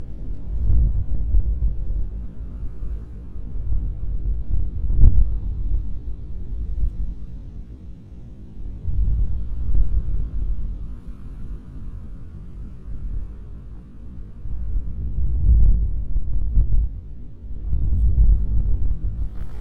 Measuring the vibrations in a secondary crater of Etna volcano we detected practically continuous vibrations, due to the activity of the volcano.
The recordings carried out by ultra-sensitive microphones showed a "rumble" of infrasound with a peak around 10-12Hz to 28dB.
In the following audio file the recorded infrasounds are transposed by software into the audible range, they appear to match the same pulses as the video images (
Obviously this process changes the frequency of sound file, but not the characteristics of pulsing vibrations.
Etna_trasposto_da_10_a_25Hz.mp3